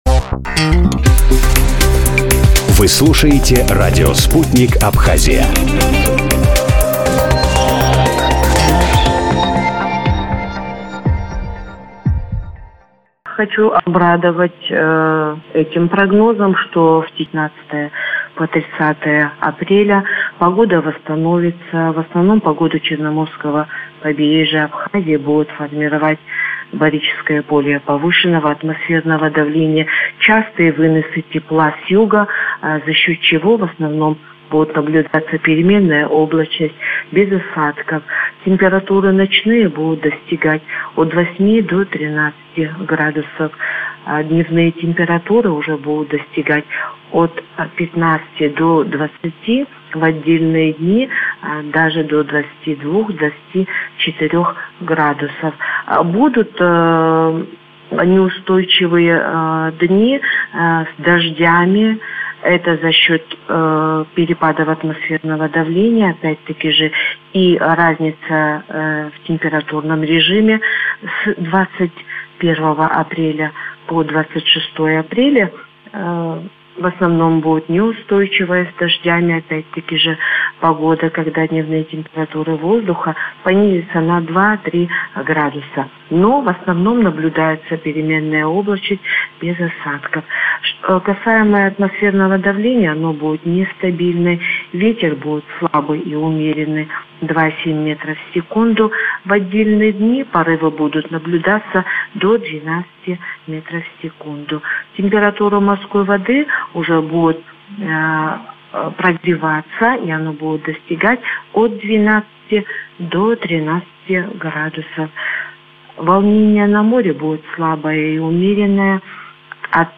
Прогнозом погоды до конца апреля в интервью радио Sputnik поделилась специалист Госкомитета Абхазии по экологии